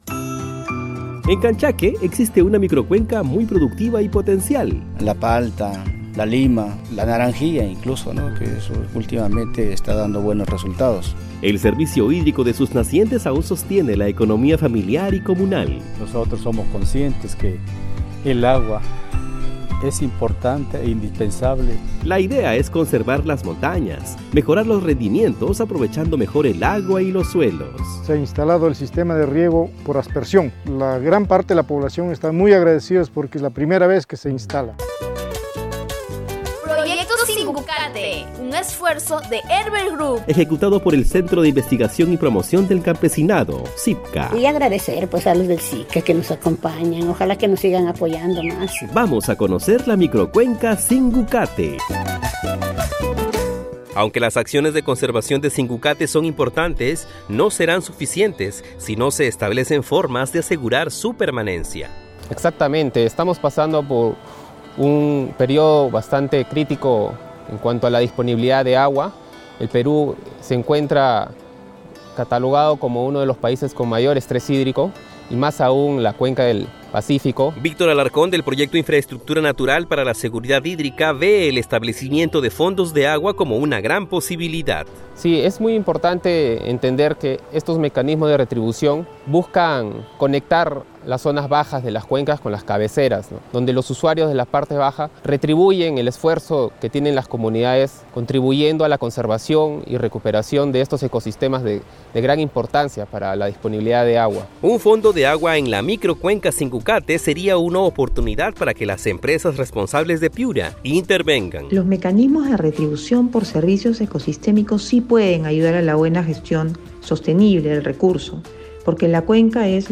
REPORTAJE-SINGUCATE-3era-parte.mp3